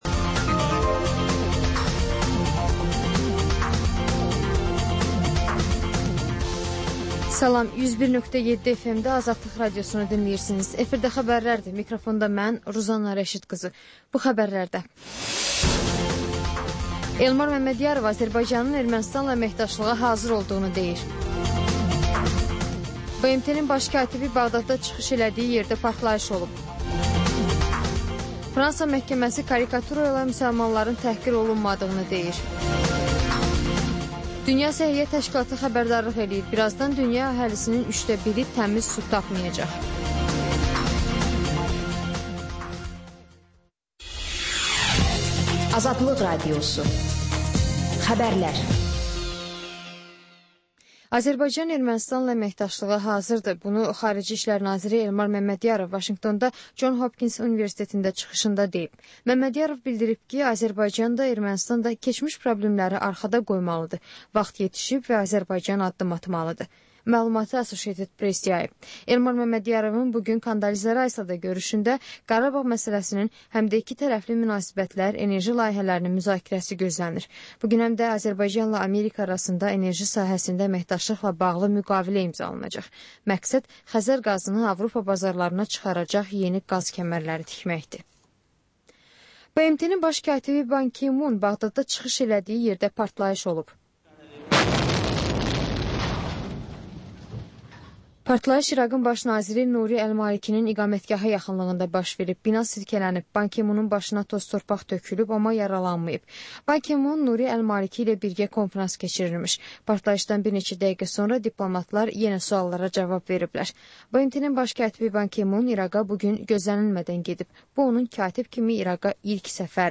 Xəbərlər, müsahibələr, hadisələrin müzakirəsi, təhlillər, sonra TANINMIŞLAR rubrikası: Ölkənin tanınmış simalarıyla söhbət